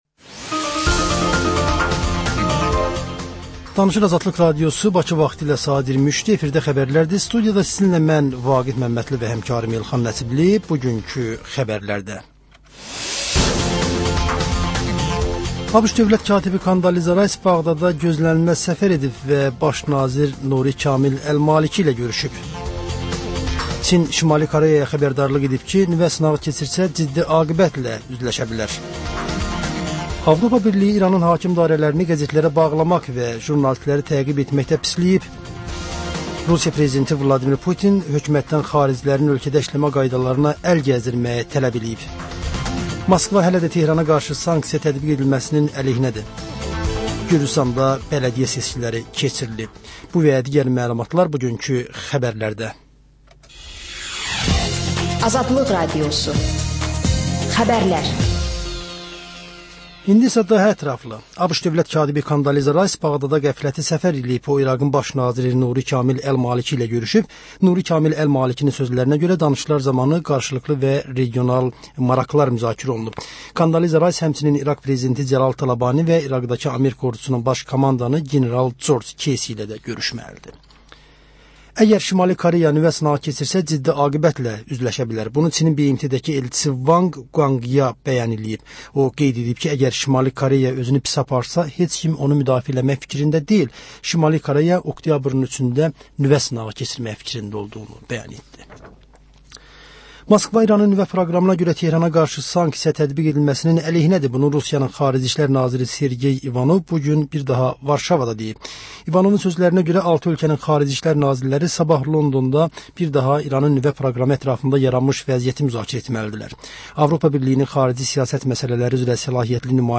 Xəbər, reportaj, müsahibə.